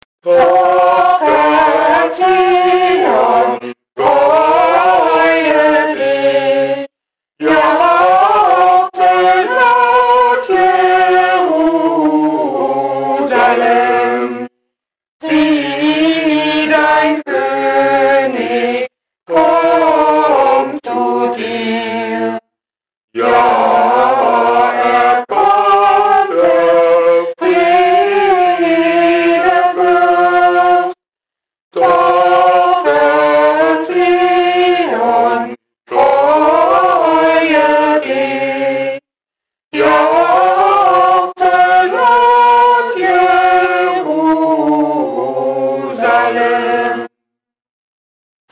• Der Jugendchor St. Nicolai unterhält musikalisch.
Weihnachtsmusik.mp3